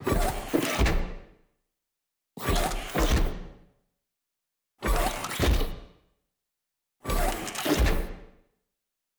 SFX_RoboSteps_Squeaky_01.wav